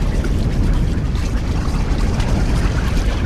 OZ_Geyser_BuildUp_Loop.ogg